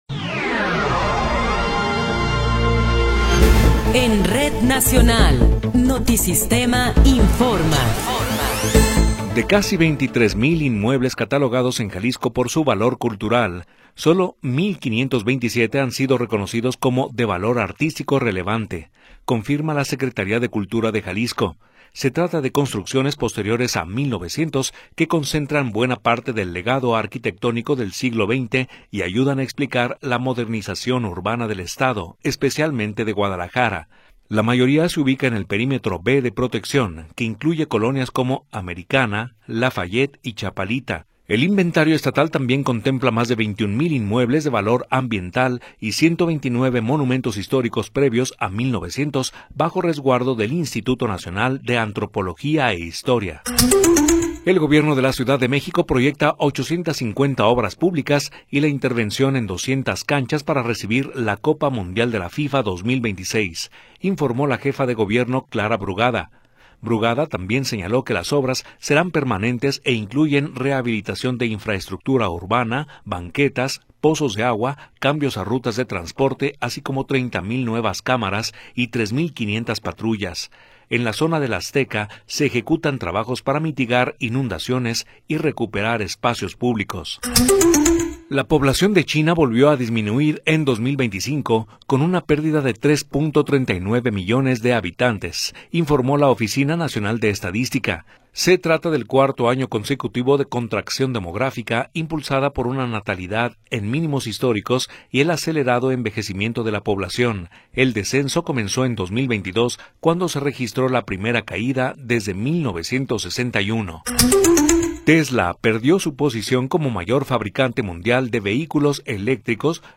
Noticiero 18 hrs. – 24 de Enero de 2026
Resumen informativo Notisistema, la mejor y más completa información cada hora en la hora.